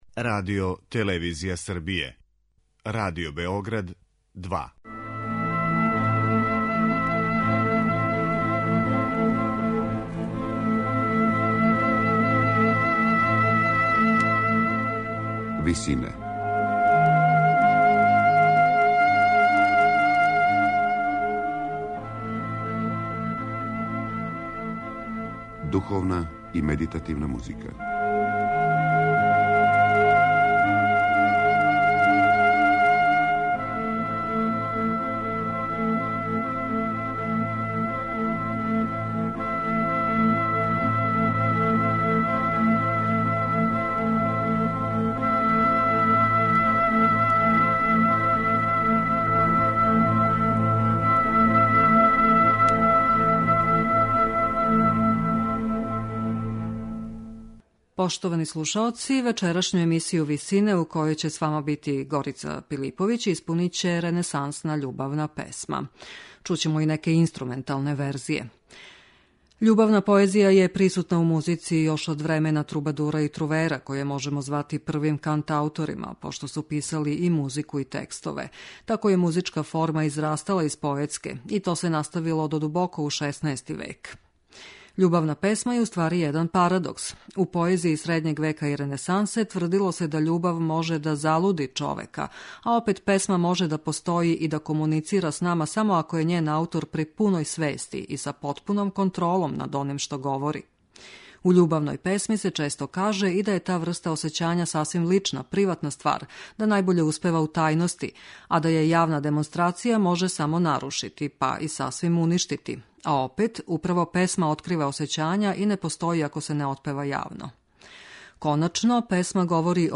Љубавне песме ренесансних композитора